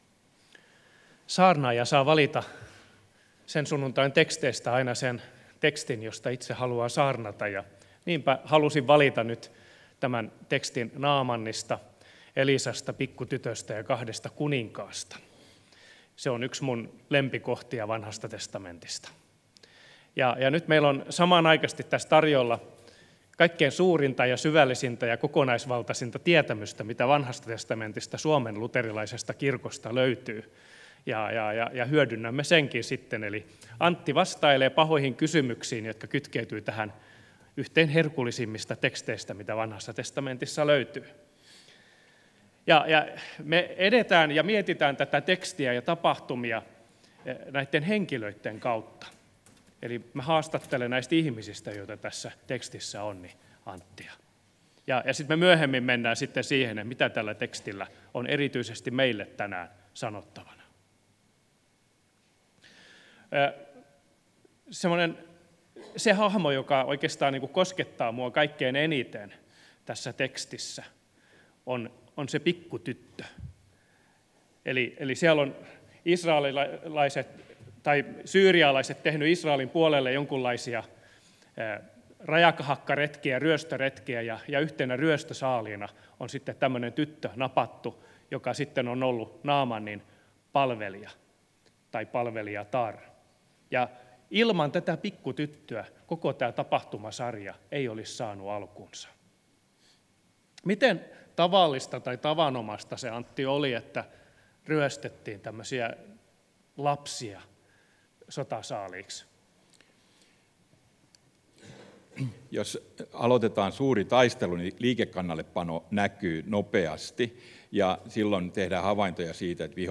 Saarna Turun Lutherinkirkossa 26.1.2020
Saarna Turun Luherinkirkolla.